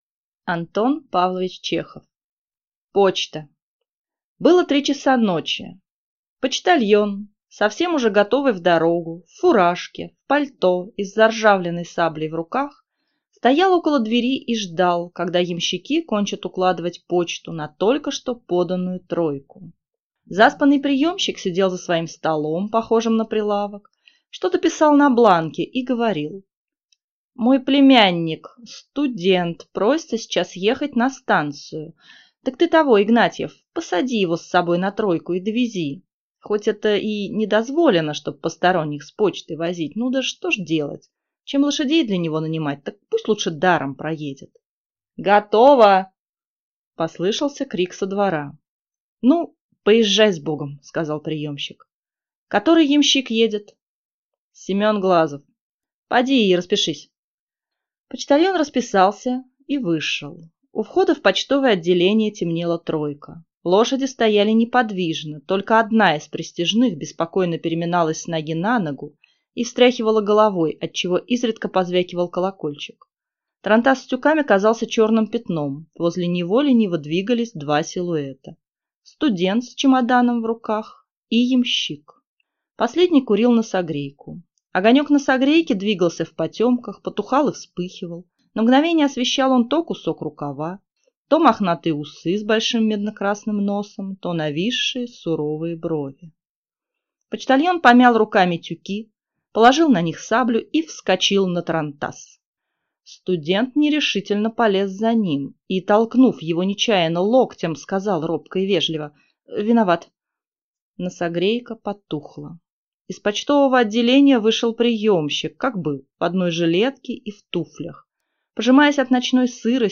Аудиокнига Почта | Библиотека аудиокниг